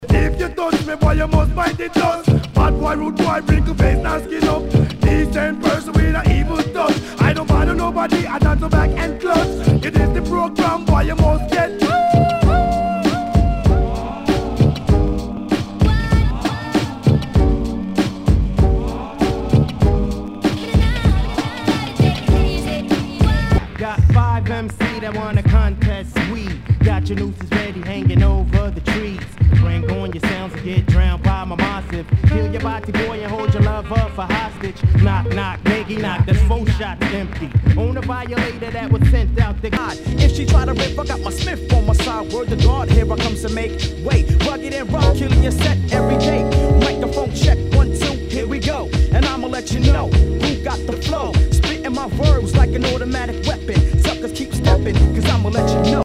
HIPHOP/R&B
全体にチリノイズが入ります。